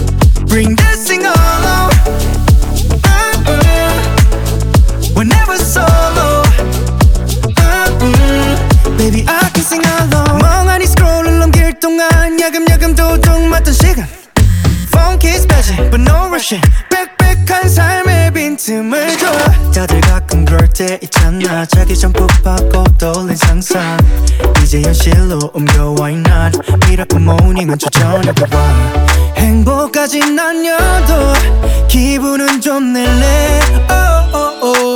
2025-07-07 Жанр: Поп музыка Длительность